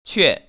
[què] 취에  ▶